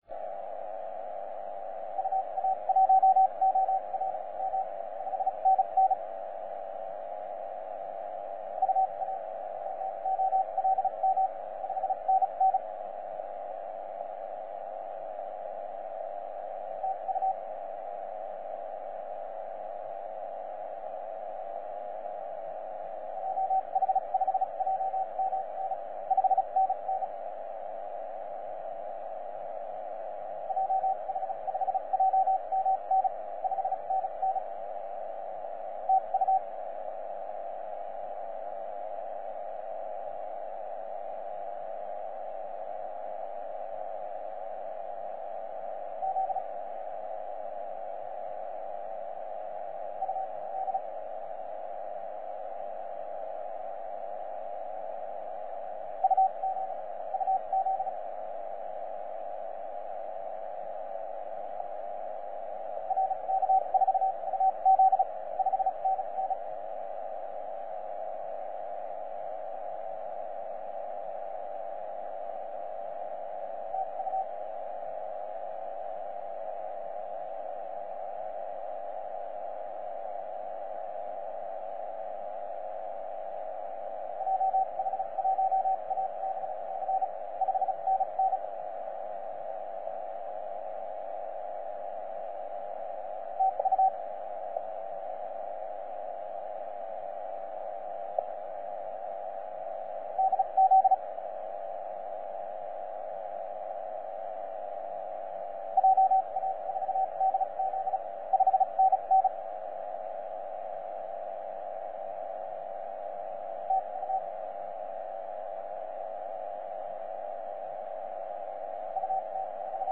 H44G 12CW